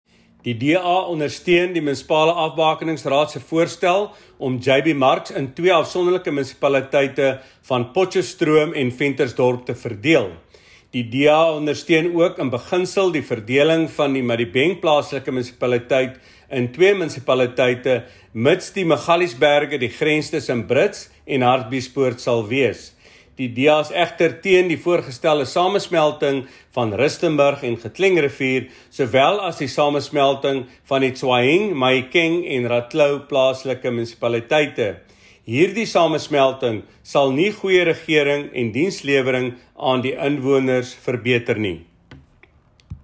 Note to Broadcasters: Find linked soundbites in English and
Afrikaans by Leon Basson MP
DANW-MDB-Submissions-Leon-Basson-AFR.mp3